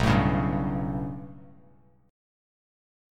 B7sus2#5 chord